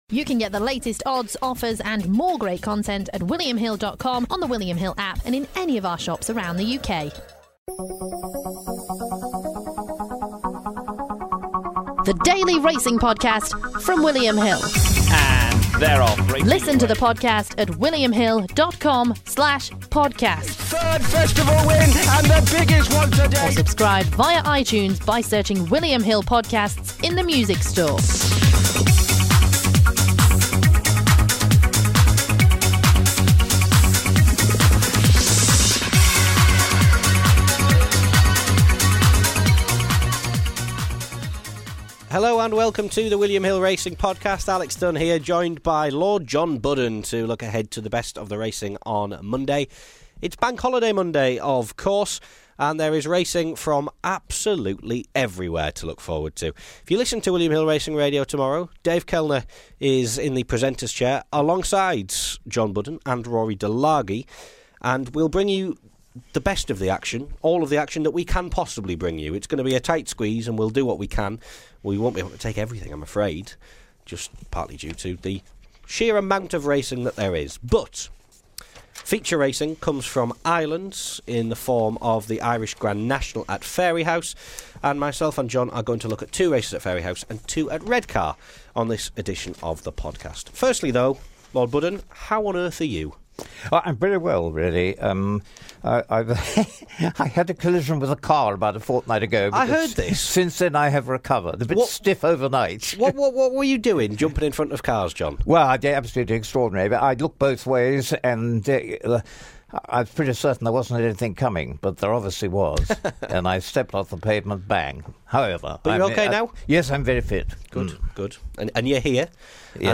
Easter Monday's Racing Podcast